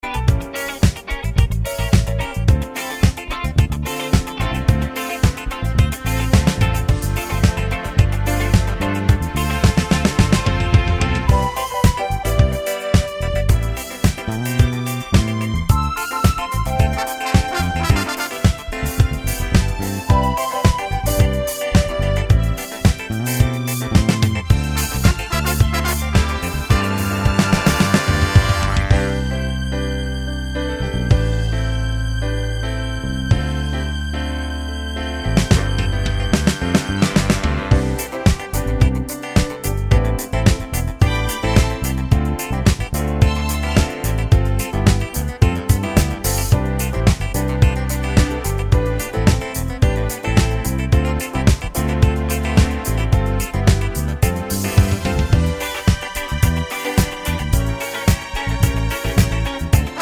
no Backing Vocals Disco 3:36 Buy £1.50